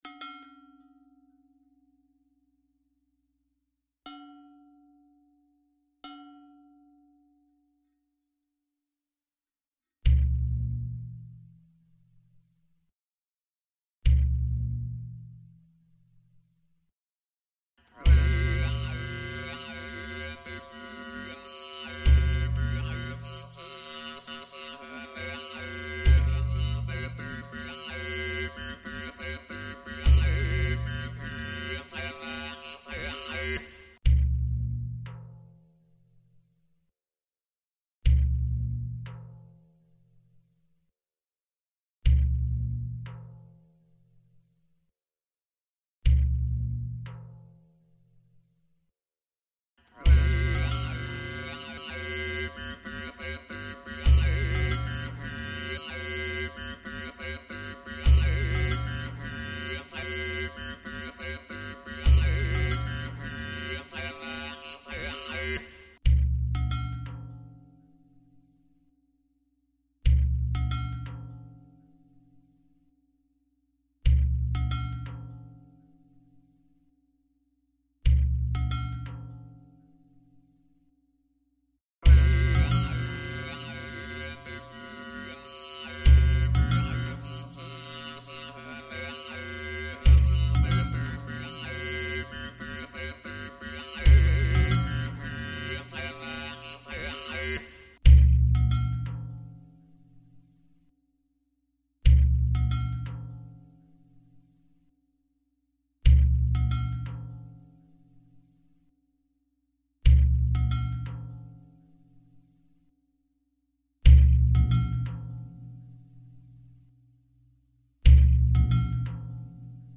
ステレオ
[コメント：ヘッドホンで音量を大きくして聞くと迫力があります]